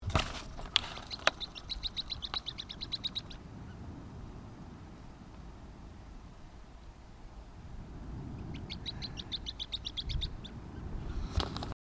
Upucerthia dumetaria dumetaria
Nome em Inglês: Scale-throated Earthcreeper
Fase da vida: Adulto
Localidade ou área protegida: Bahía Bustamante
Condição: Selvagem
Certeza: Observado, Gravado Vocal
Bandurrita-Comun.mp3